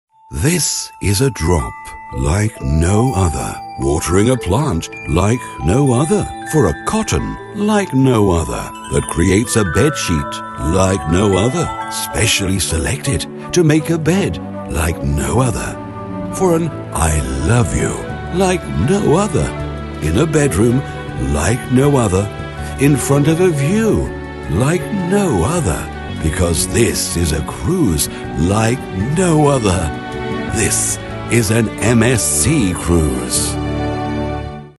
Native speakers
Engels (vk)